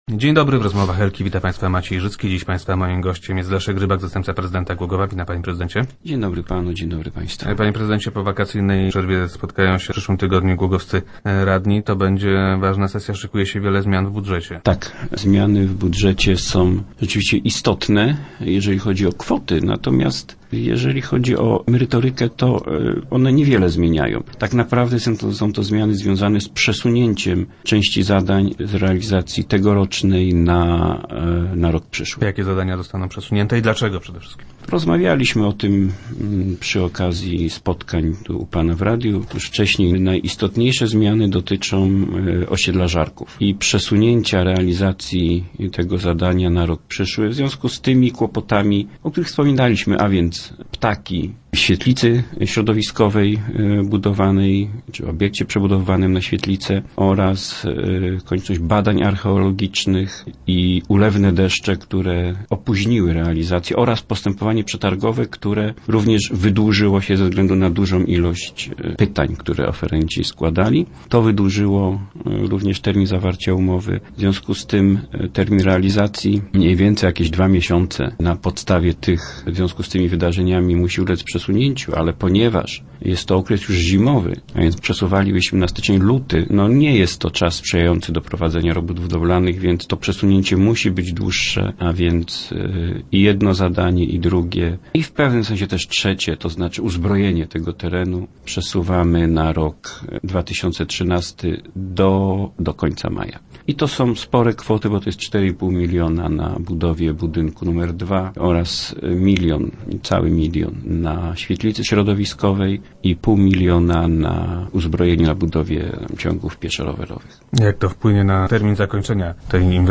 - Nie wszystkie są zależne od nas - twierdzi Leszek Rybak, zastępca prezydenta Głogowa, który był gościem Rozmów Elki.